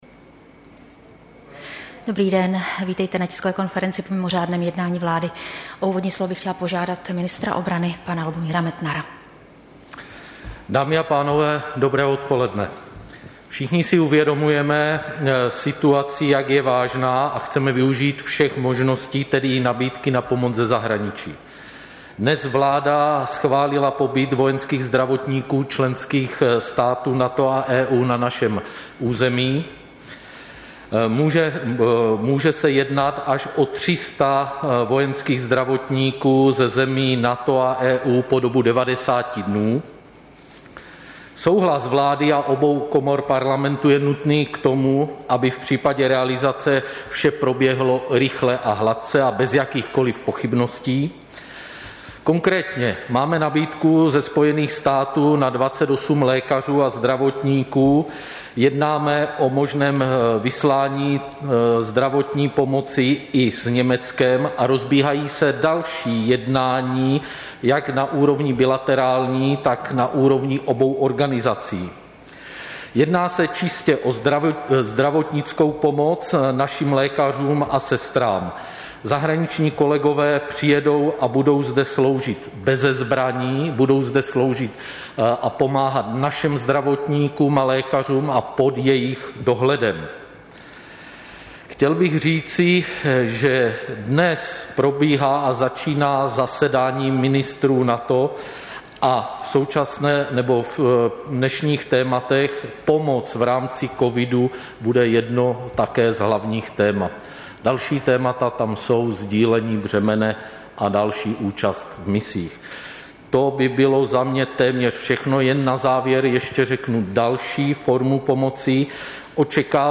Tisková konference po mimořádném jednání vlády, 22. října 2020